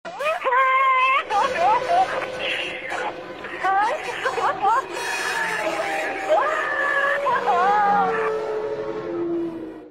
チェッカーを受けた瞬間、バリチェロは泣いていた。
チーム無線では泣き叫びながら感情を伝えた。
その時の無線の様子